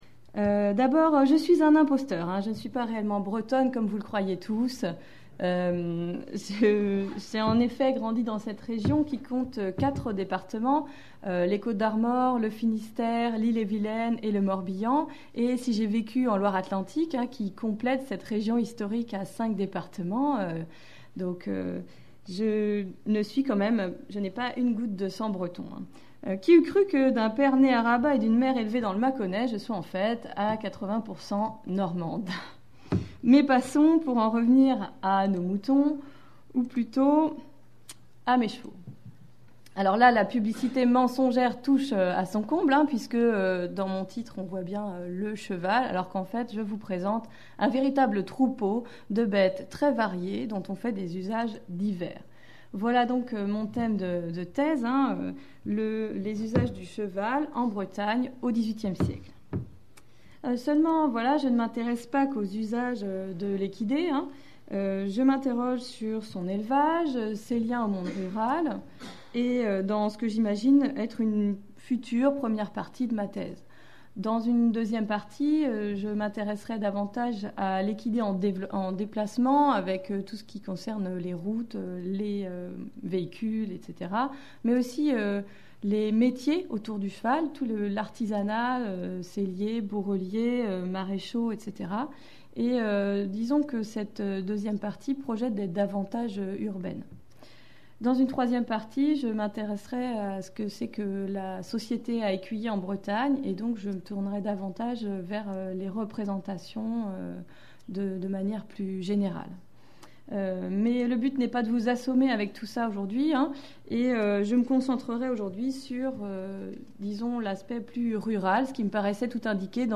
La présentation effectuée dans le cadre du séminaire du pôle rural aborde plus précisément le rôle du cheval dans le monde rural.